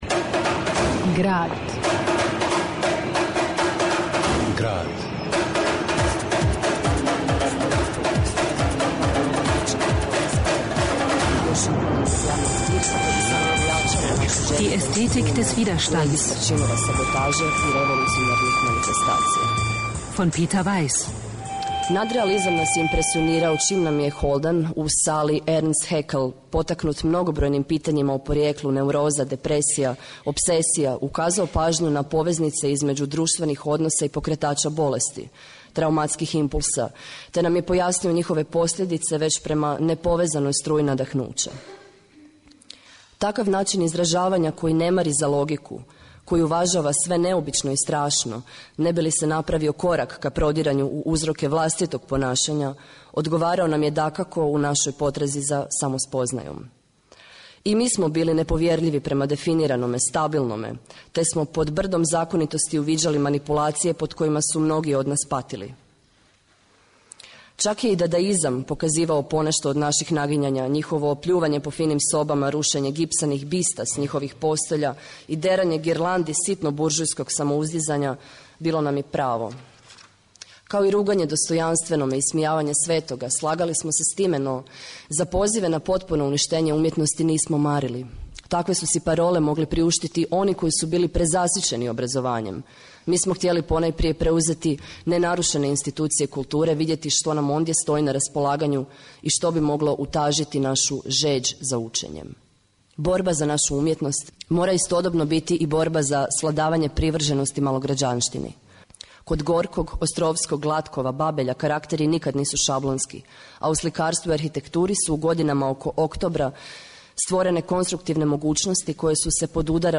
Полазећи од тротомног историјског романа Петера Вајса, Естетика отпора (Peter Weiss , Die Aesthetik des Widerstands ), дебата у ЦЗКД била је о томе како су различите политичке секвенце повезане и како су условиле једна другу. Дебата је била део пројекта берлинског HAU театра.